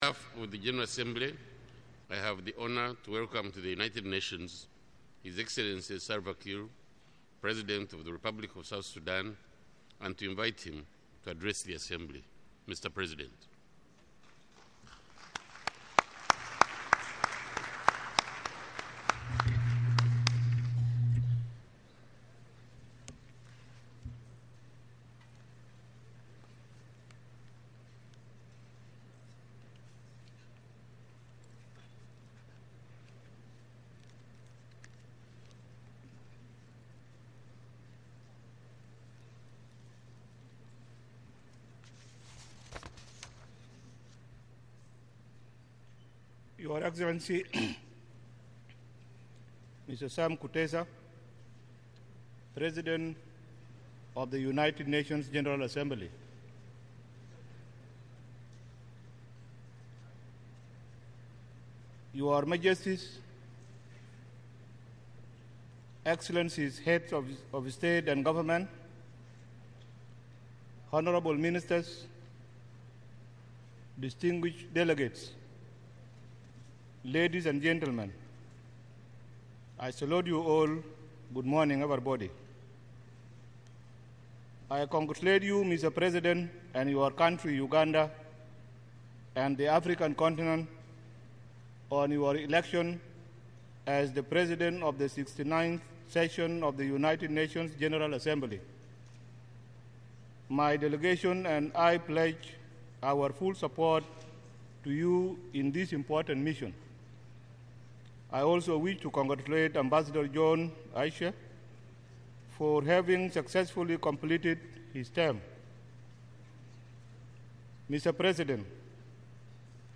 President Salva Kiir's speech to the United Nations General Assembly